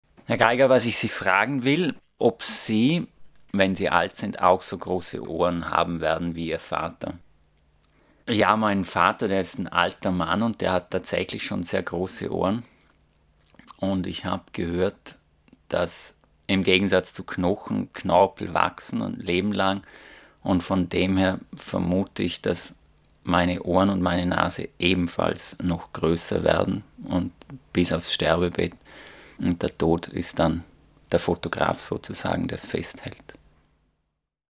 Er hat über 90 Schriftsteller gebeten, sich selbst eine Frage zu stellen und sie dann zu beantworten.
Deutschlandradio Kultur sendet die Fragen und Antworten als Mini-Selbstgespräche in der Zeit vom 8.10. bis 14.10.07 jeweils in "Fazit am Abend" und in "Fazit" - parallel zur Frankfurter Buchmesse.